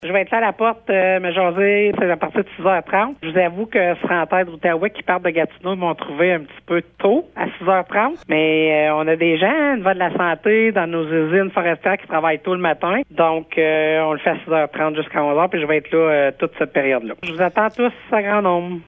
La préfète Chantal Lamarche explique comment s’est déroulé le processus qui a mené vers la concrétisation de ce tout premier Déjeuner de la préfète par Centraide Outaouais :